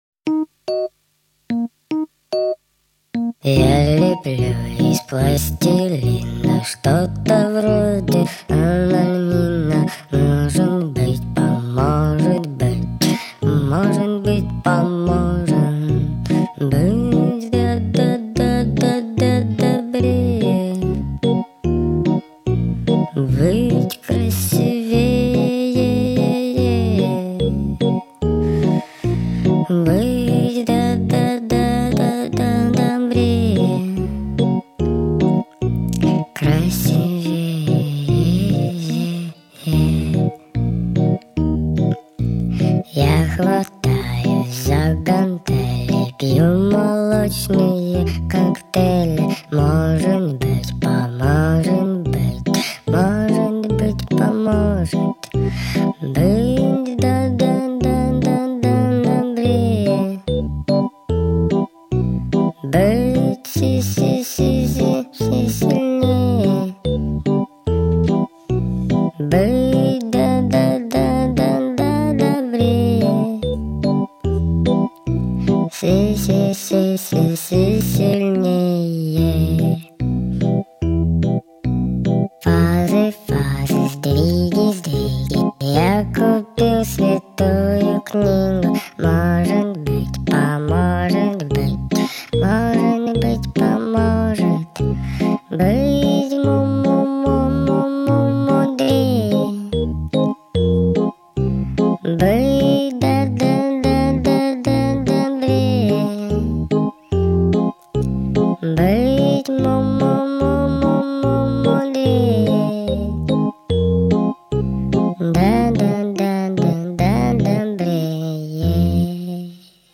[12/7/2009]非常奇怪的歌 歌手睡着了 激动社区，陪你一起慢慢变老！